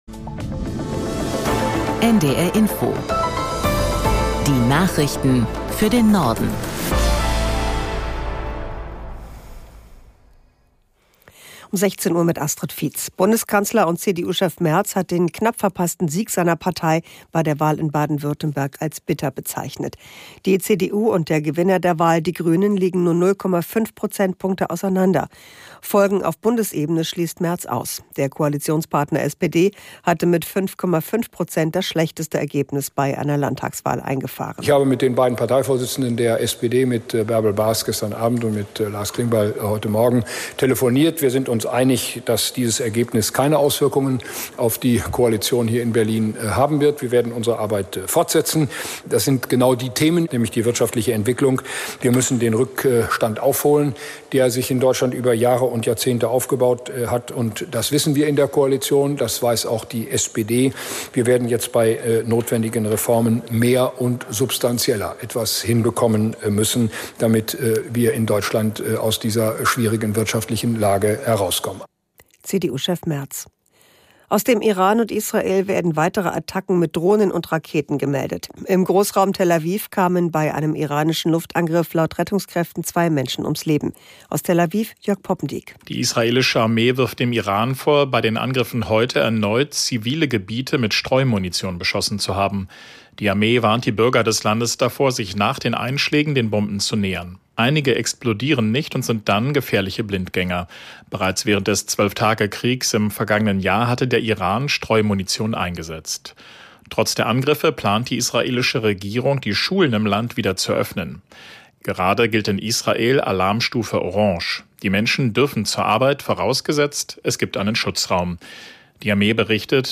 Die aktuellen Meldungen aus der NDR Info Nachrichtenredaktion.